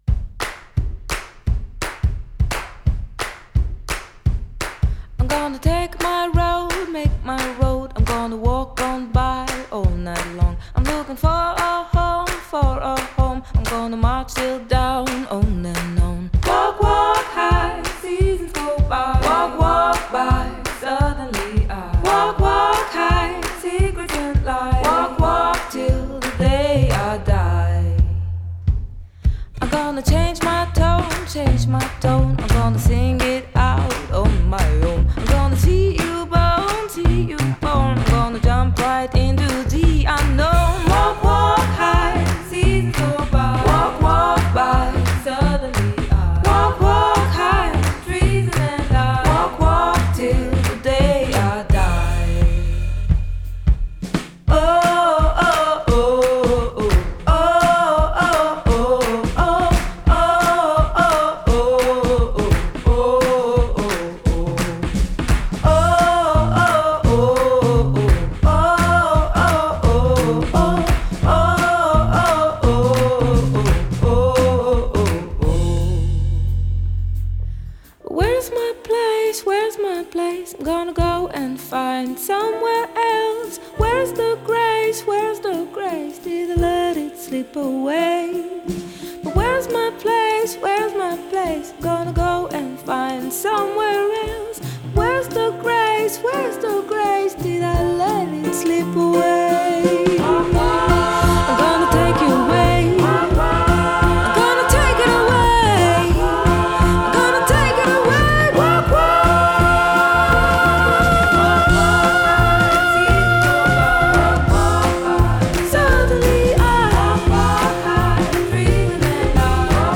Жанр: Indie, Folk, Pop
Genre: Female vocalists, Indie, Folk, Pop